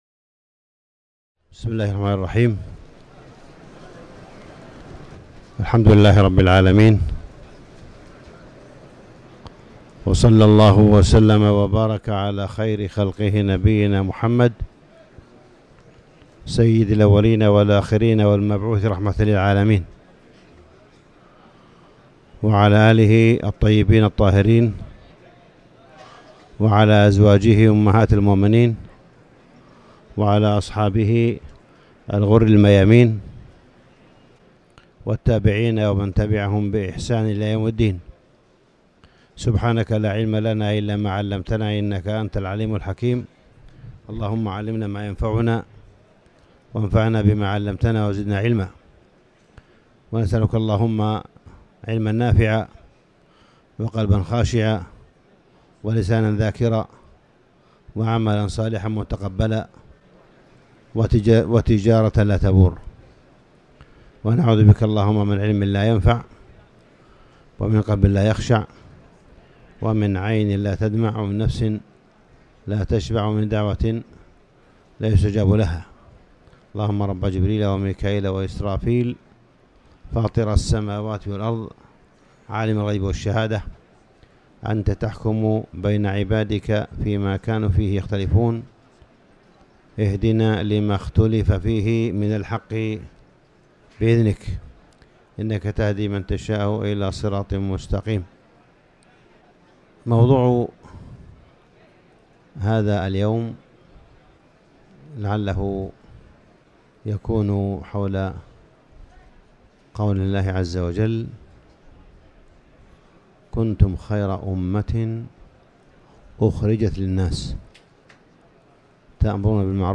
4ذوالحجة-محاضرة-معالم-الخيرية-في-أمة-الوسطية-1.mp3